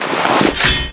backpack_armour.wav